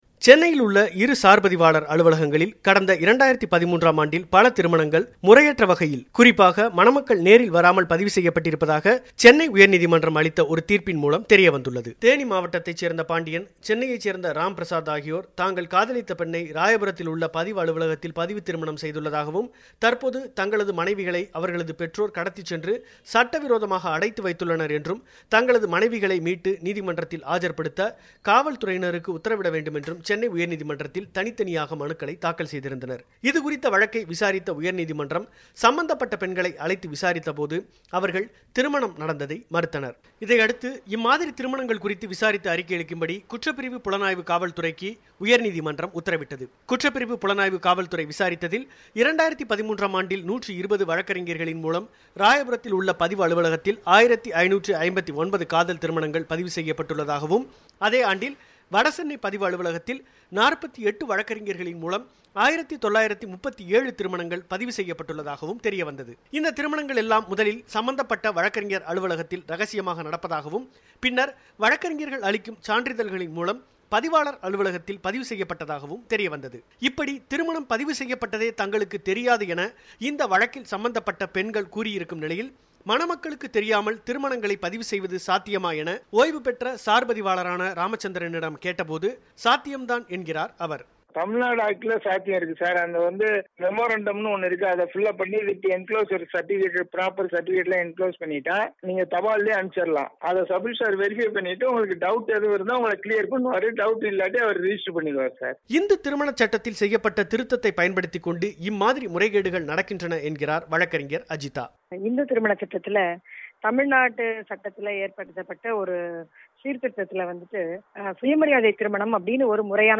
சென்னையில் ஓராண்டில் மூவாயிரம் போலி பதிவுத் திருமணங்கள் - ஒலிப் பெட்டகம்